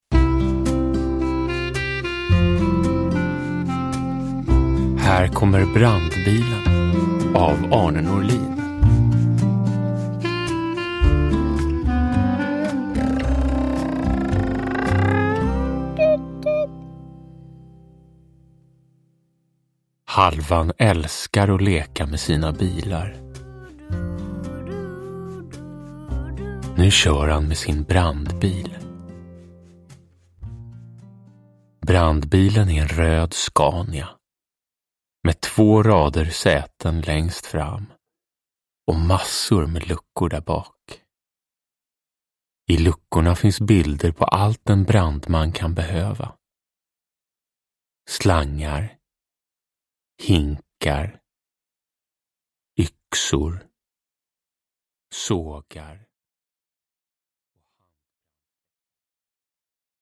Uppläsare: Jonas Karlsson